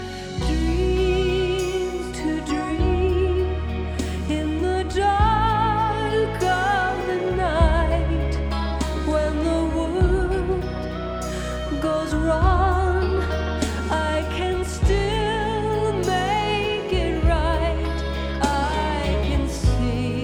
Tukaj sem naredil par fileov za testiranje kvalitete mojega kodeka, mp3-ja in original wave-a (CDja).
Originalen wave rip cdja pri 1.0x hitrosti 44.1kHz Stereo 3.40MB